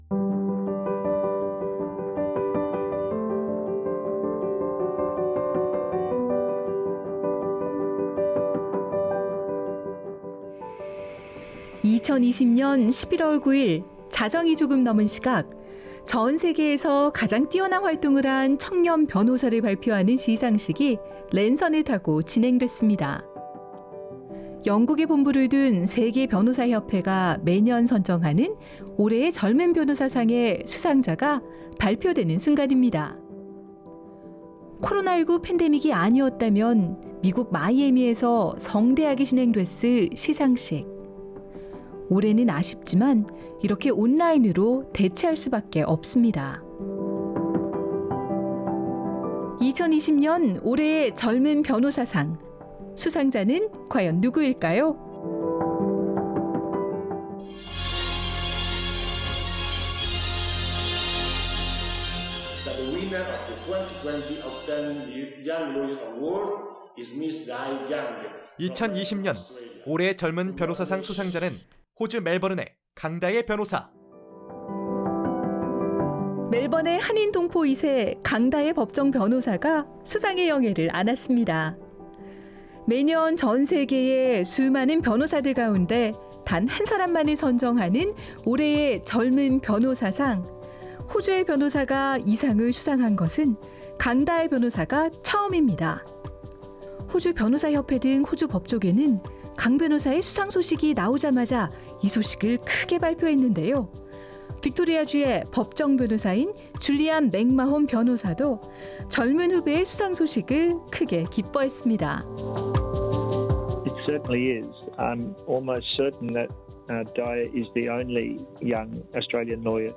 오늘은 특집 다큐멘터리 ‘팬데믹 속에서도 개척은 계속’으로 다시 만나봅니다.